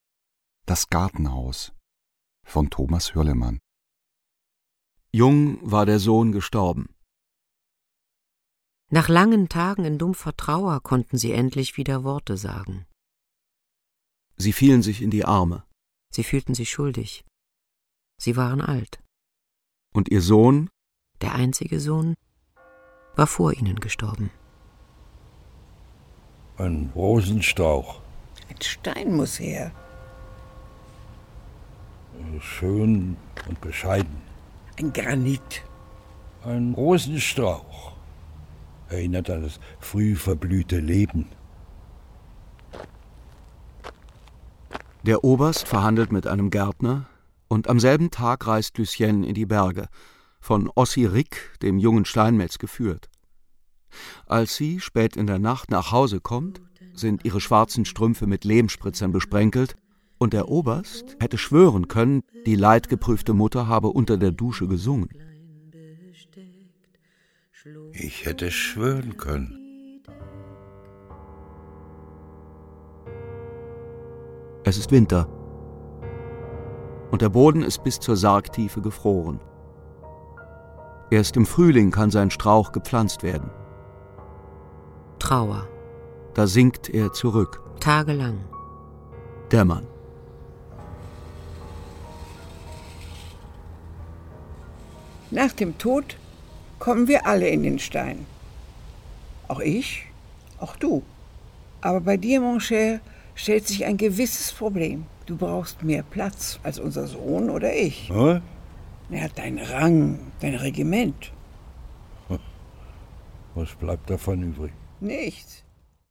Das grandios besetzte Hörspiel setzt Hürlimanns melancholische, mit liebevoller Ironie geschriebene Novelle gekonnt um.
Sprecher: Fritz Lichtenhahn, Nadja Tiller, Ulrich Matthes, Dagmar Manzel